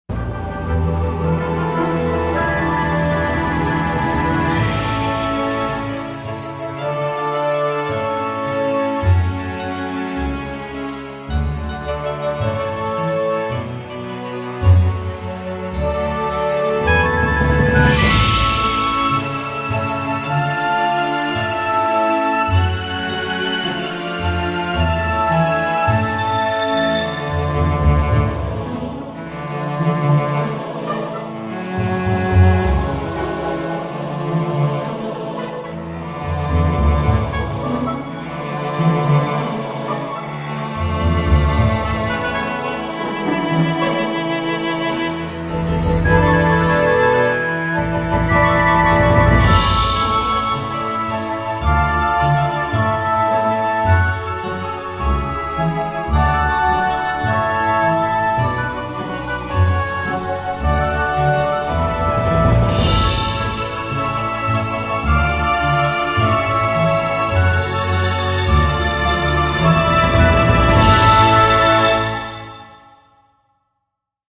orchestral soundtrack